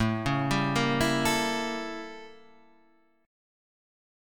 A 7th Flat 9th